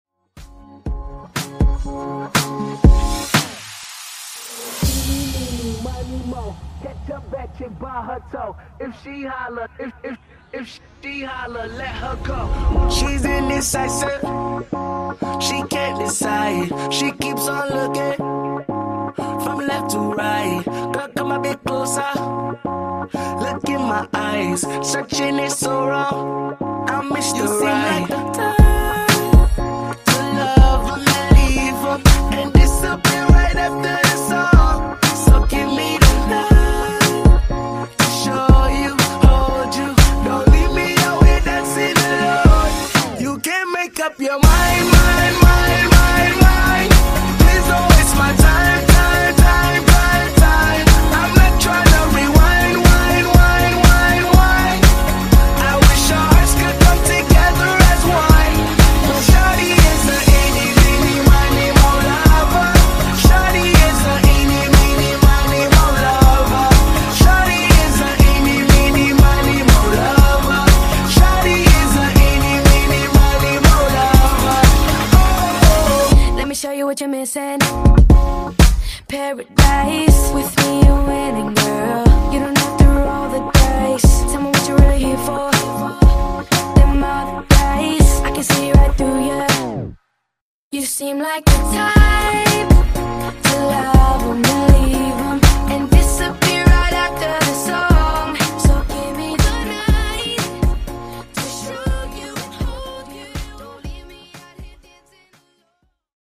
BPM: 121 Time